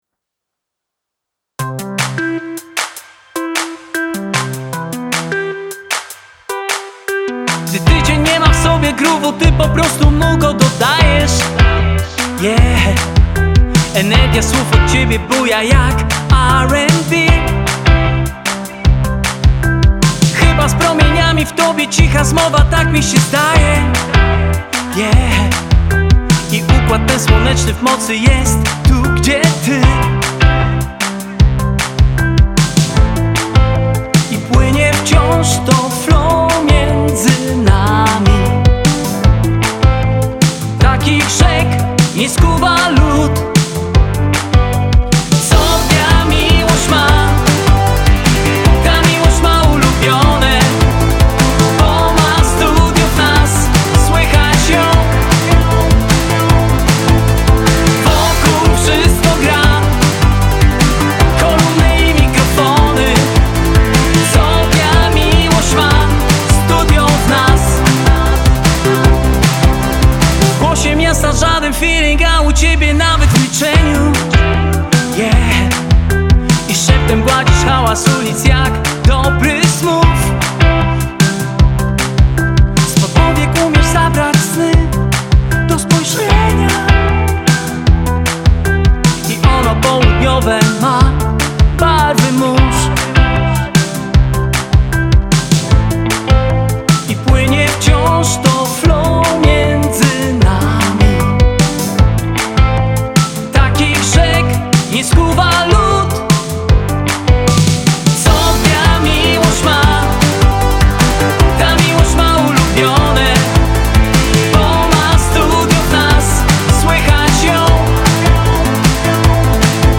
Singiel (Radio)
Lekki, przyjemny, dźwięczny disco-pop.